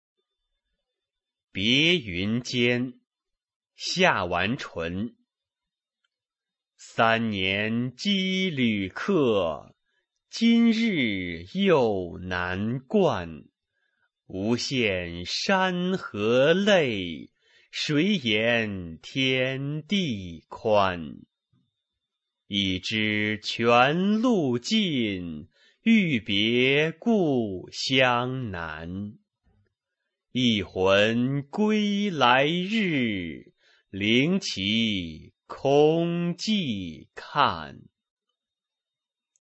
夏完淳《别云间》原文和译文（含赏析、朗读）